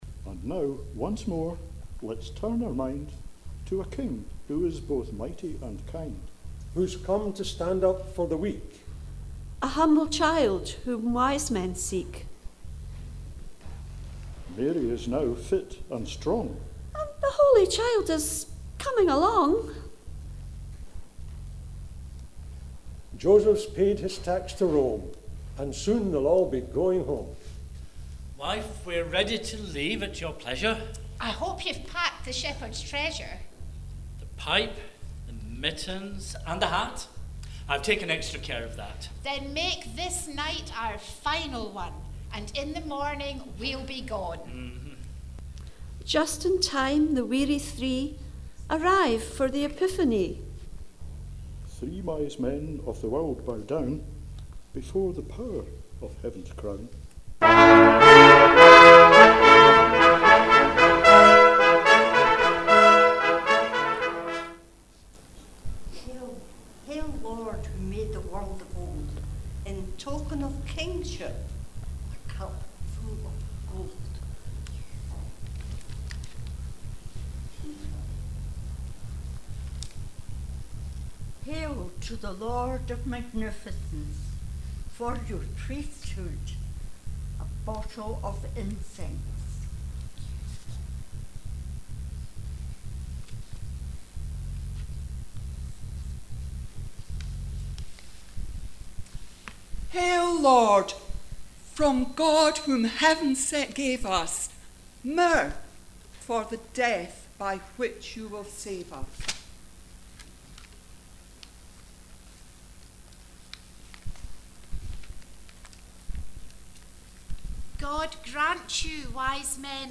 We return to Bethlehem where Mary, Joseph and the child Jesus are preparing to return home, after paying their tax to the  Roman's. The wisemen appear to a trumpet fanfare, kneeling and offering gifts of gold, incense, and Myrrh.
As they rest the angels appear, to the strains of 'Gloria in Excelsis', to warn the wisemen not to return to Herod.